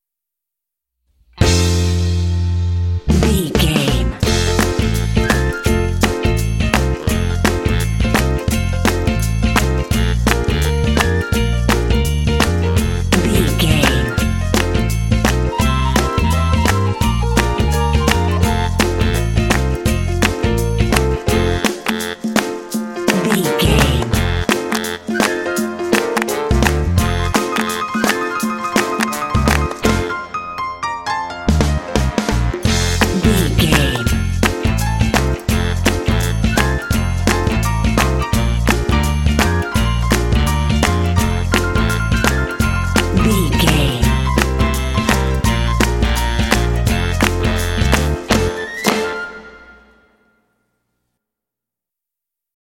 Uplifting
Ionian/Major
fun
happy
bouncy
groovy
piano
drums
flute
bass guitar
saxophone
quirky
kitschy